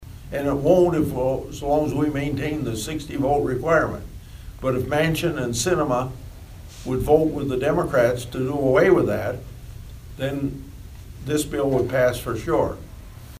Grassley made his comments Tuesday during a stop in Audubon as part of his annual 99-county tour.